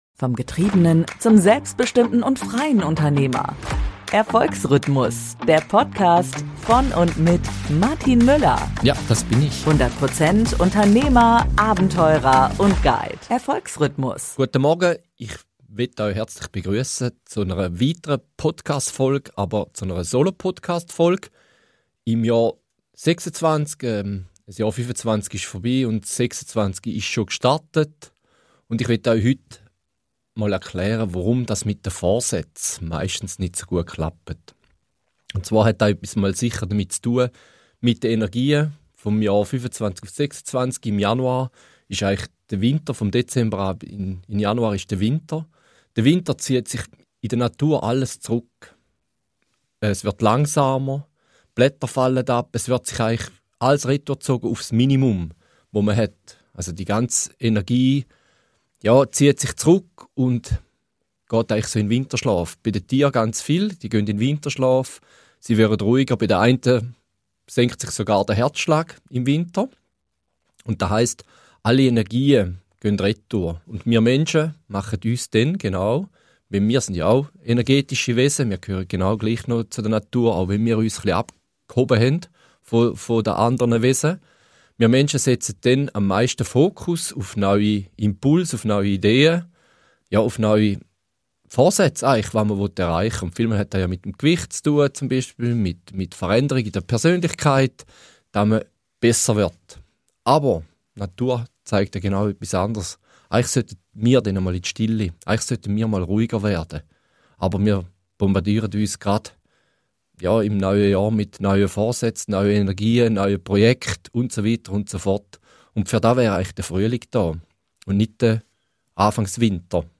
In dieser besonderen Solo-Podcast-Folge erkläre ich, warum die meisten Neujahrsvorsätze bereits im Februar scheitern – und was die Natur uns über den richtigen Zeitpunkt für Veränderungen lehrt.